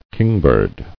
[king·bird]